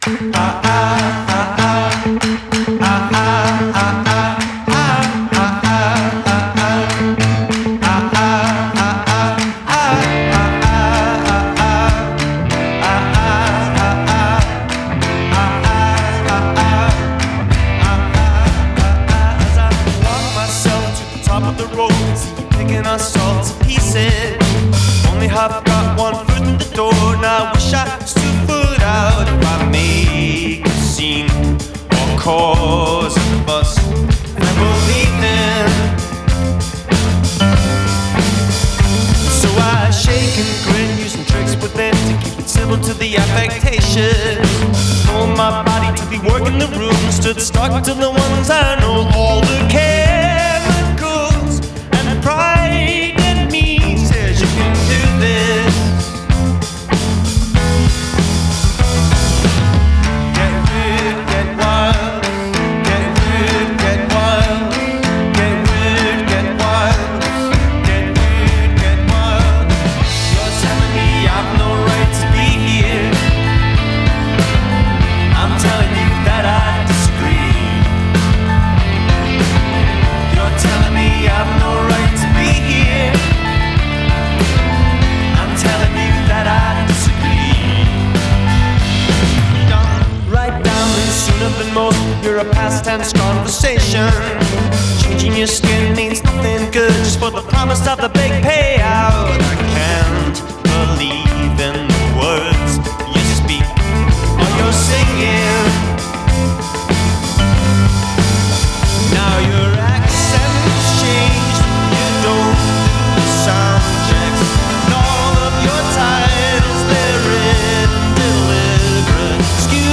heartfelt electronic indie rock music